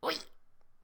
capri_whee.ogg